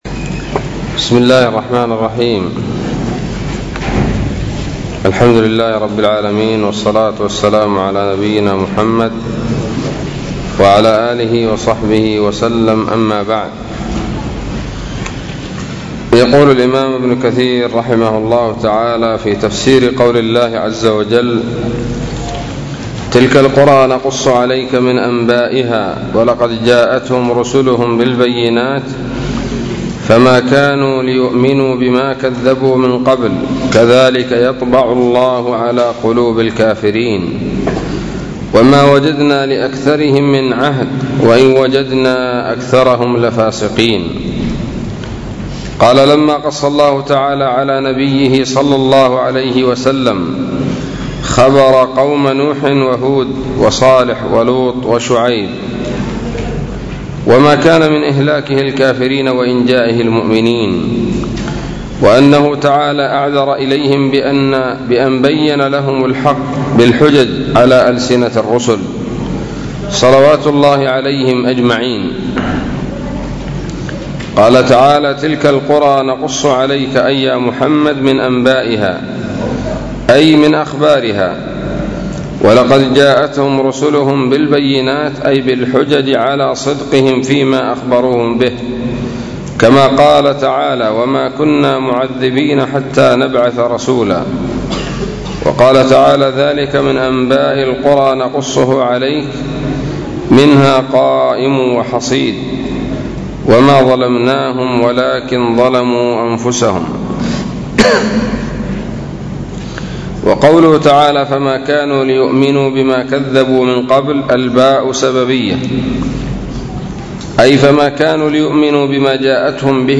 الدرس الثامن والثلاثون من سورة الأعراف من تفسير ابن كثير رحمه الله تعالى